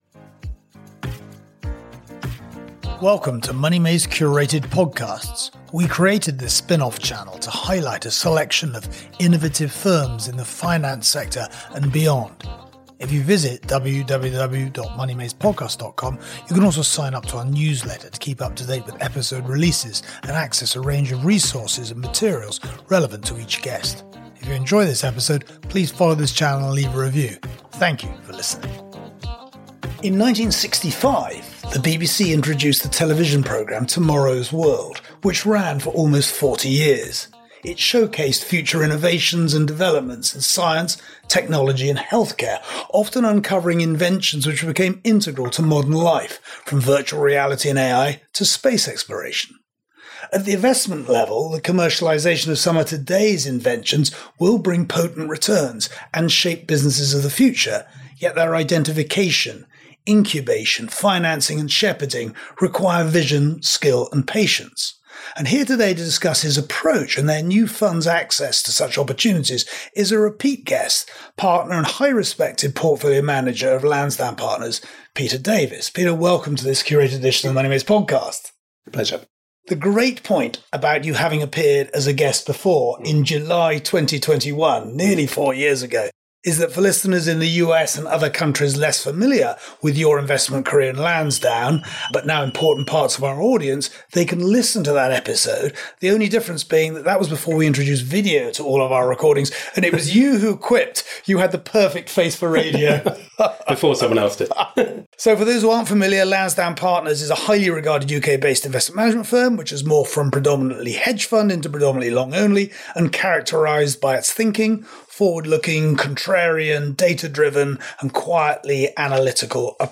Today, the commercialization of groundbreaking inventions presents significant investment opportunities, but identifying, funding, and nurturing them demands vision, skill, and patience. In this conversation